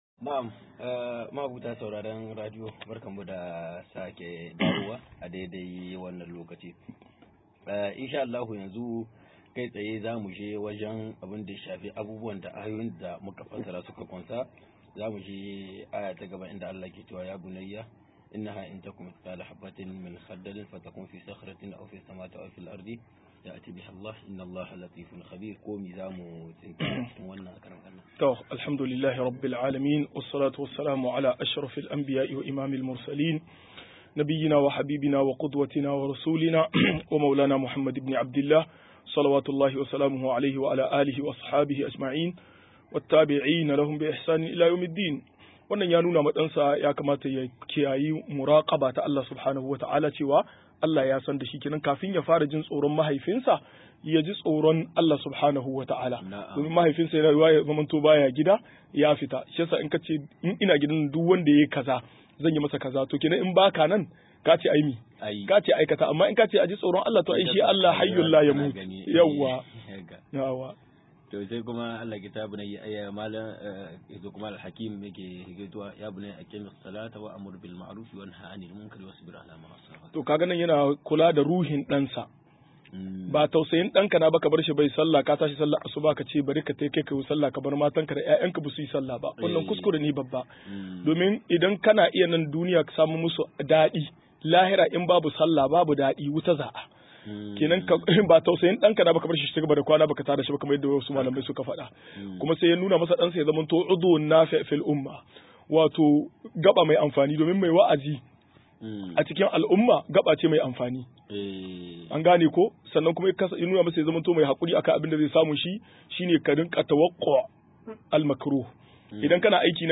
152-Fa idodin Tarbiya a Kissar Year Maryam - MUHADARA